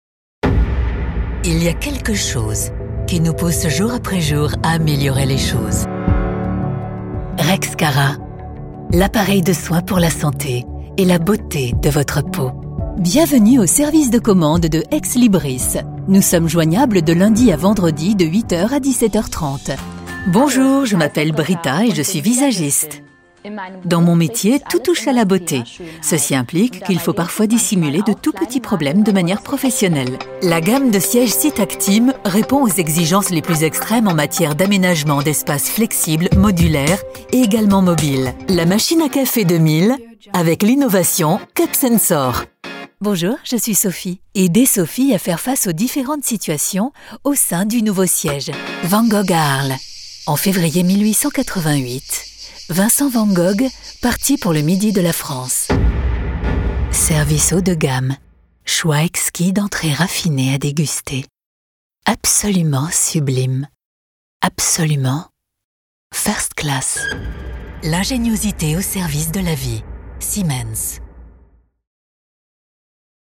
Französische Sprecherin. Flexible Stimme.
Sprechprobe: Industrie (Muttersprache):
French native Voice artist with professional Home Studio.